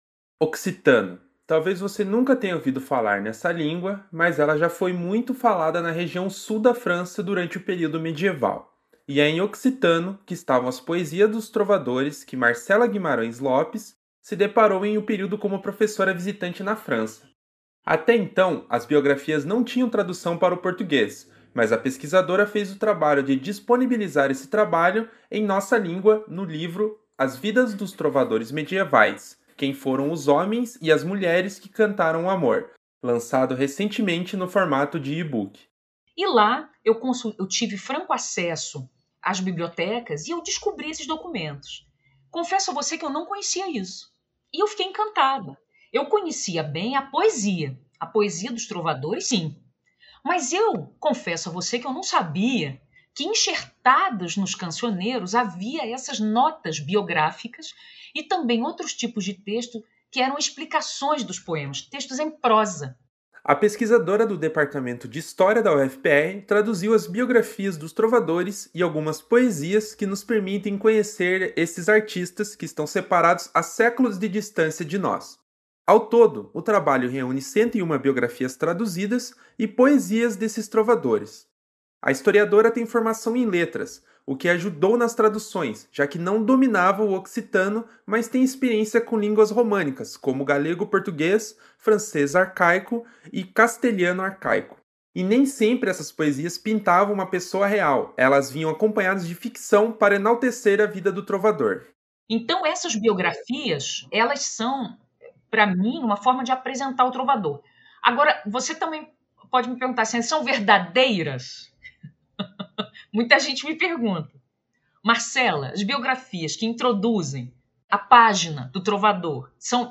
parceria Rede Aerp de Notícias e Agência Escola UFPR